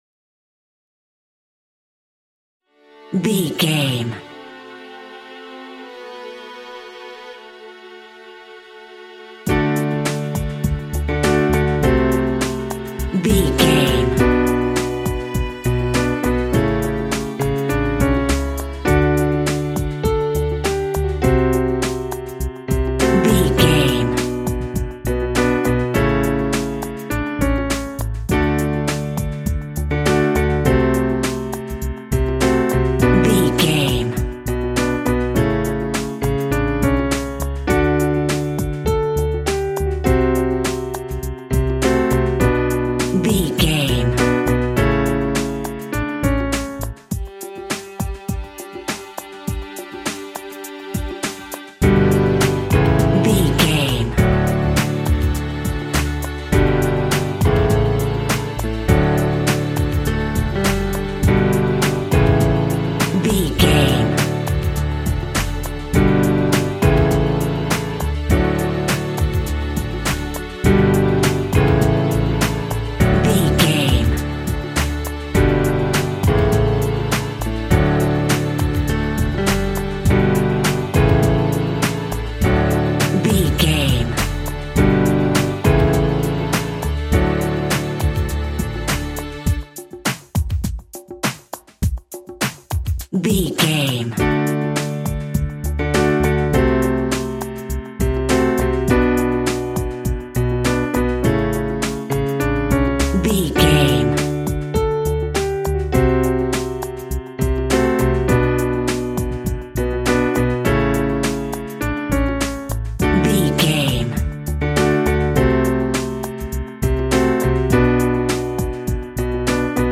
R+B Pop Boy Band Ballad.
Ionian/Major
D
cheesy
Teen pop
electro pop
pop rock
drums
bass guitar
electric guitar
piano
hammond organ